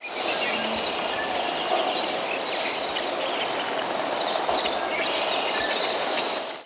Chipping Sparrow diurnal flight calls
Diurnal calling sequences:
Bird in flight.